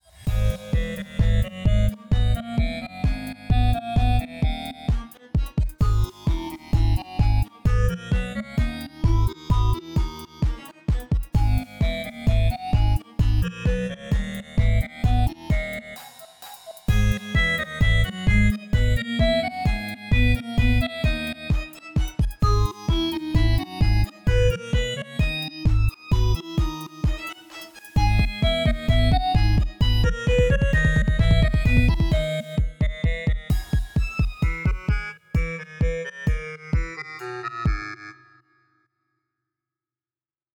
• Качество: 320, Stereo
веселые
EDM
без слов
experimental
электронные
простые
Такая себе мелодия, сделанная в фл